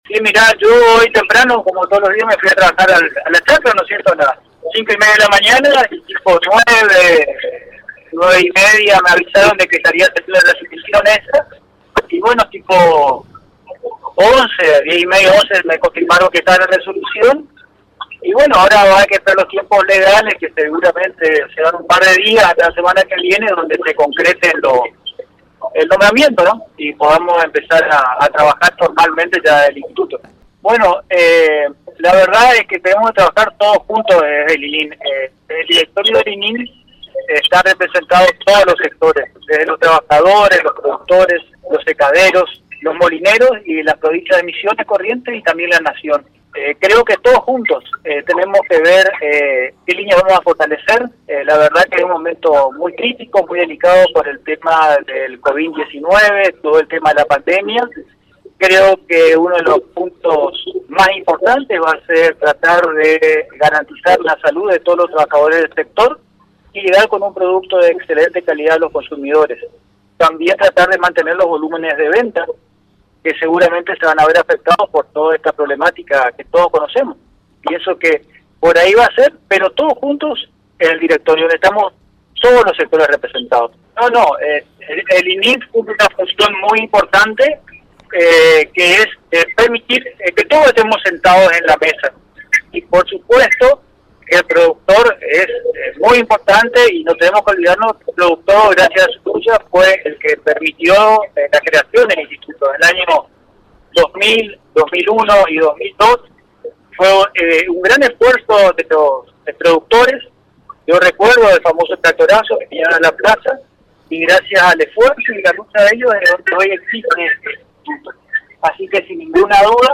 El flamante Presidente del INYM Juan José Szychowski charló en exclusiva con Fm Band News 89.7 y la ANG en Infonews